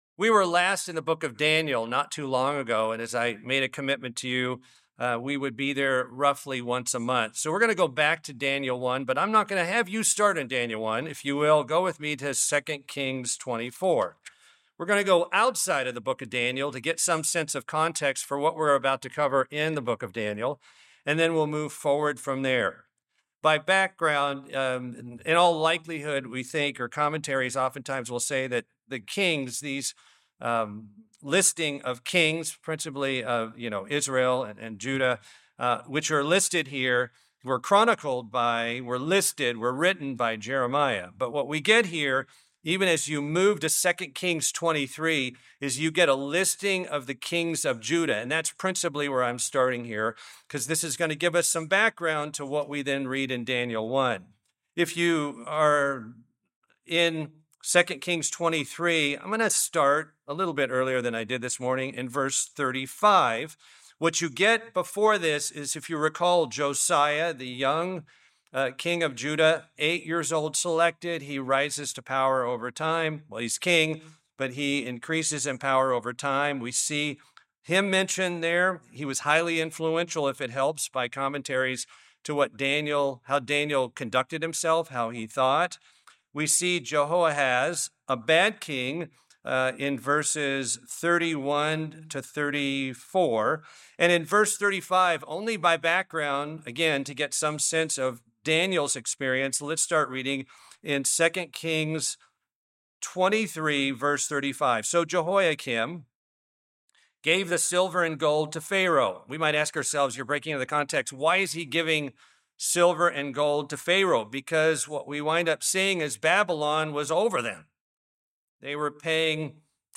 In a new series of sermons covering the Book of Daniel, a background is provided and Daniel's commitment to obeying God is discussed.